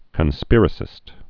(kən-spîrə-sĭst)